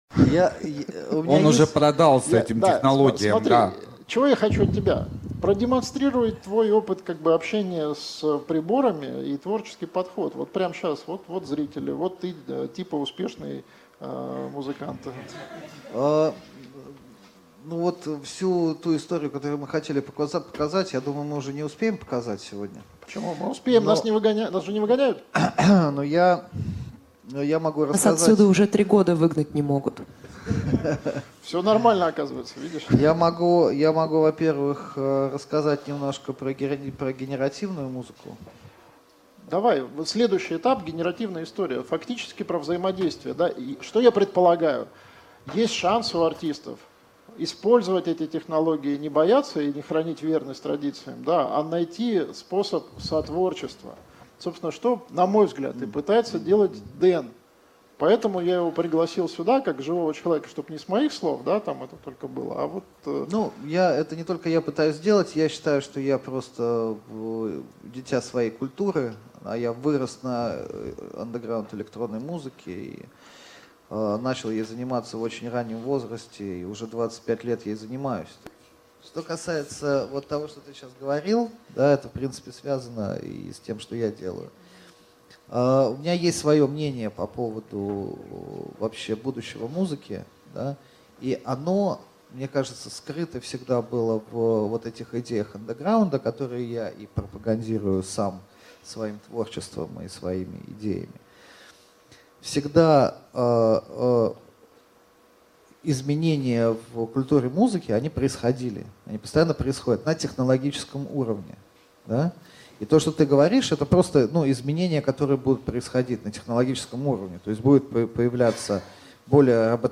Аудиокнига Музыка.